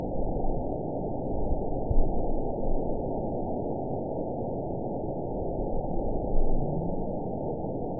event 911053 date 02/09/22 time 00:47:46 GMT (3 years, 3 months ago) score 9.20 location TSS-AB01 detected by nrw target species NRW annotations +NRW Spectrogram: Frequency (kHz) vs. Time (s) audio not available .wav